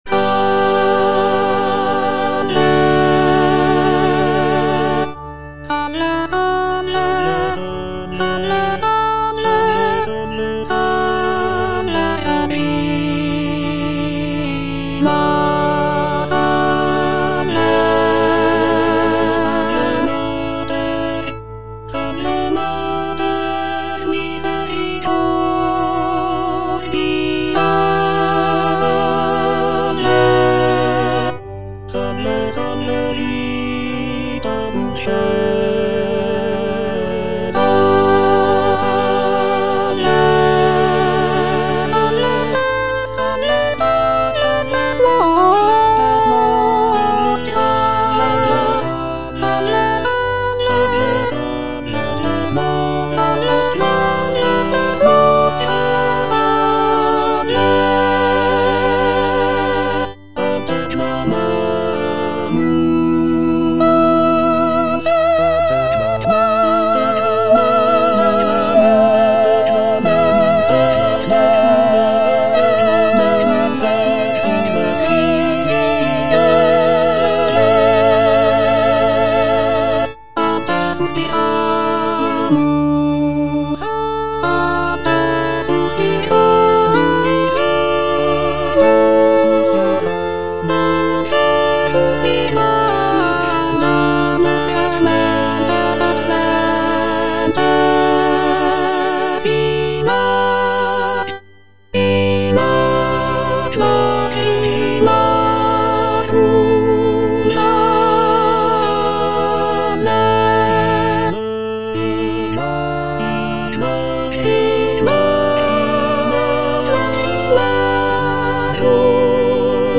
G.F. SANCES - SALVE, REGINA Soprano (Emphasised voice and other voices) Ads stop: auto-stop
"Salve, Regina" by Giovanni Felice Sances is a sacred vocal work written in the 17th century.
The piece is written for soprano soloist and continuo accompaniment, and features a lyrical and expressive melody that showcases the singer's vocal abilities.